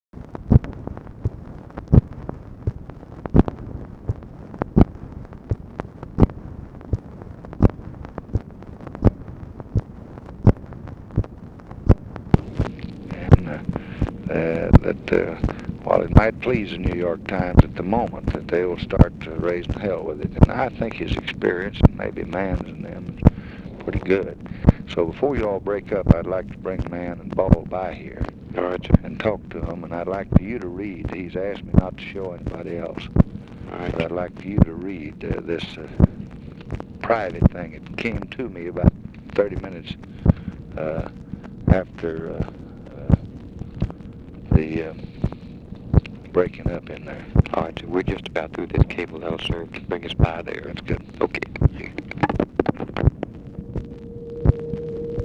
Conversation with BILL MOYERS, May 22, 1965
Secret White House Tapes